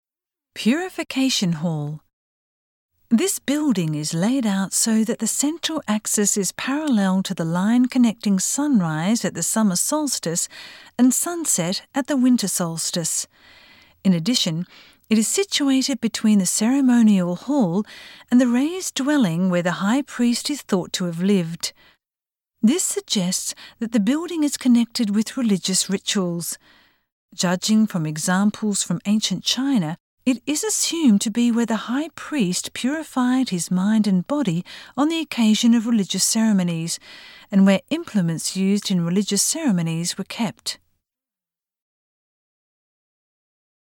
Voice guide